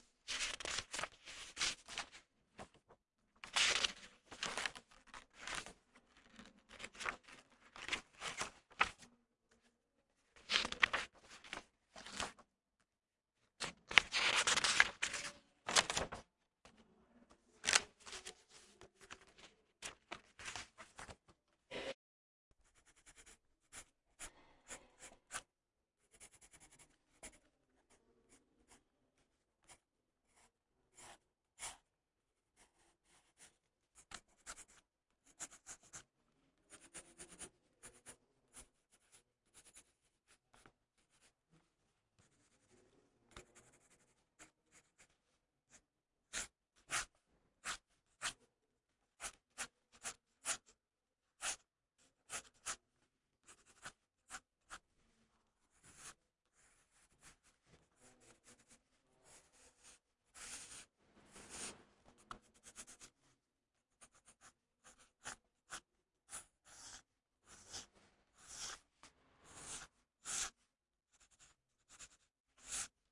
铅笔在纸上的划痕写作
描述：用于短片，一名男子正在博物馆中绘制缩放H5和Sennheiser K6 ME66在Adobe Audition中进行规范化和清洁
Tag: 绘图 搔抓 铅笔